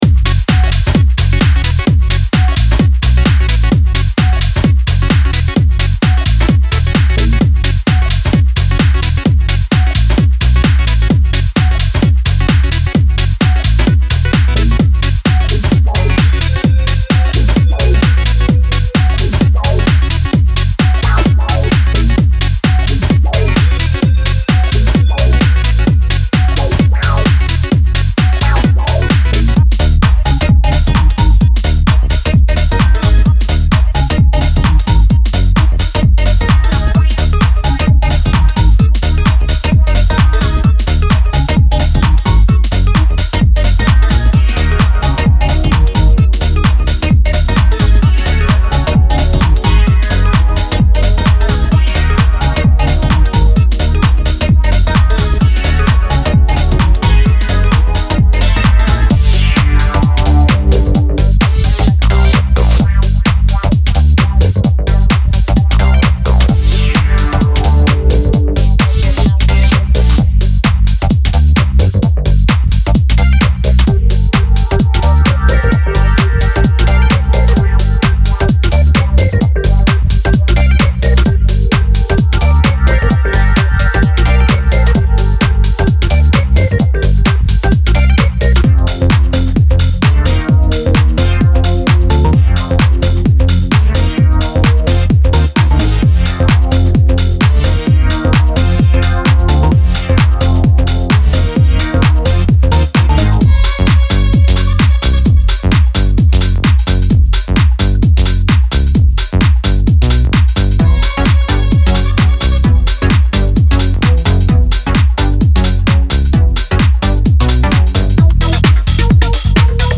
所有循环乐段的播放速度均为130bpm。